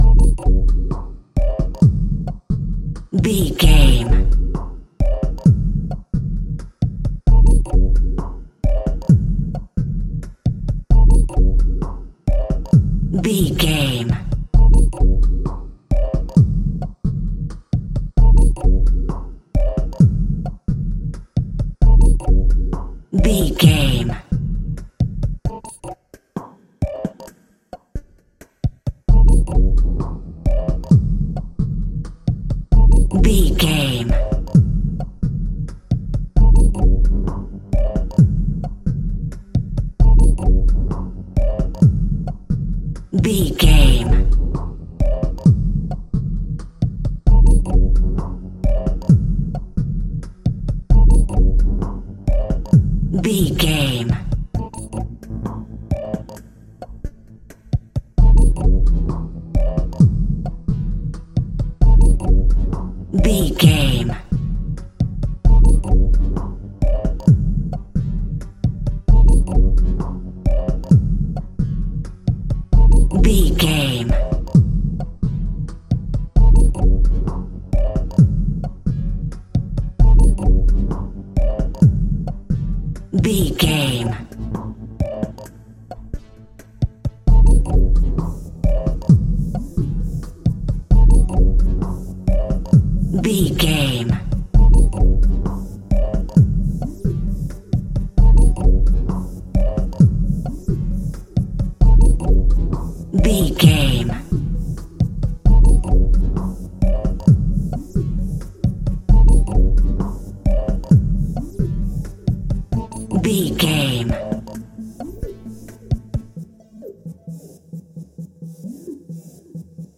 Atonal
scary
tension
ominous
dark
suspense
eerie
synthesizer
percussion
mysterious
beeps